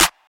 Clap [Metro].wav